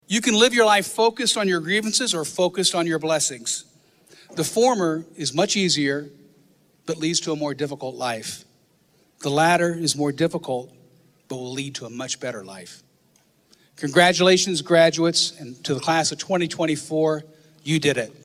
The VU class of 2024 attended Commencement on Saturday.